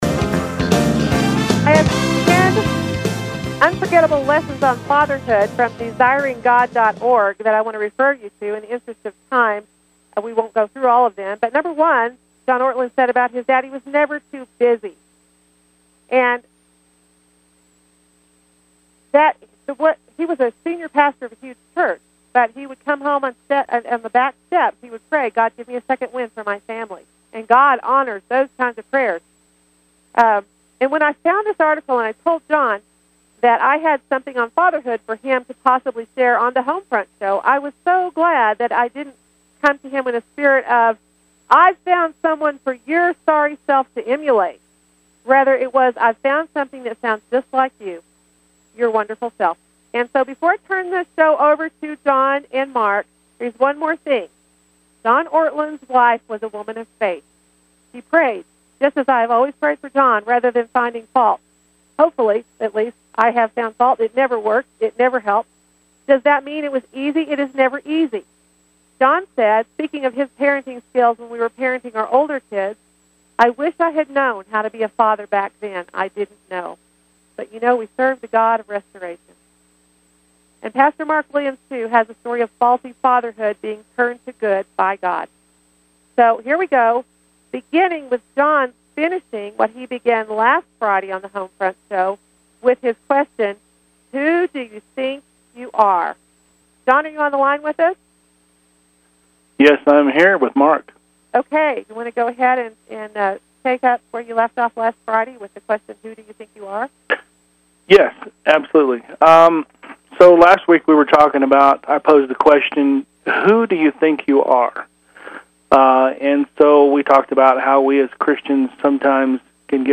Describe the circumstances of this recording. Airs on Fridays at 2:00 mountain time, on KHNC 1360, Johnstown Colorado.